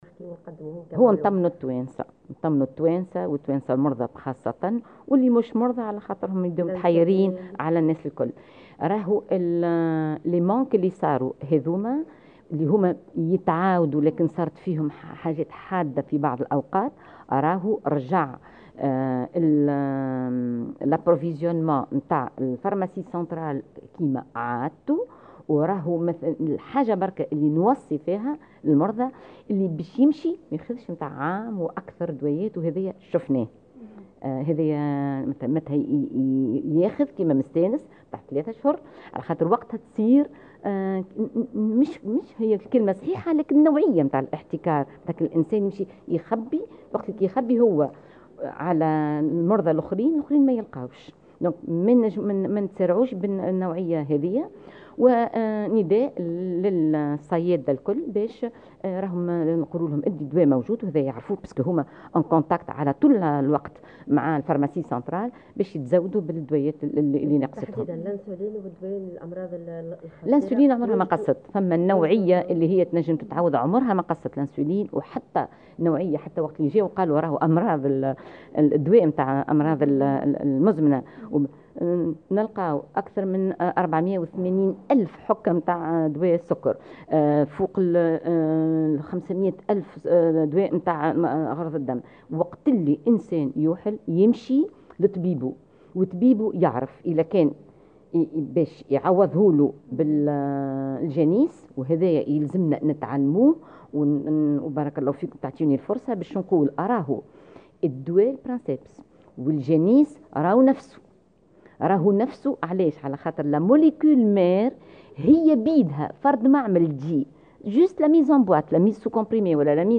قالت المديرة العامة للصحة نبيهة بورصالي فلفول اليوم 14 أوت في تصريح لمراسلة الجوهرة 'اف ام' إنّ مخزون الأدوية في الصيدلية المركزية لا يعاني من أي نقص و إنّ كل الأدوية متوفرة في الوقت الحالي.